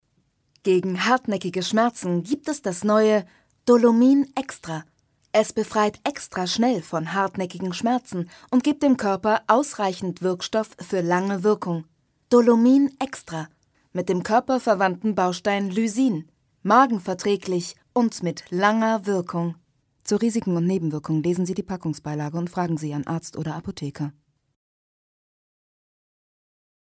Sprecherin für Hörspiel/ Synchron Stimmfarbe: jung, aufgeweckt,lebendig und sinnlich
Sprechprobe: Werbung (Muttersprache):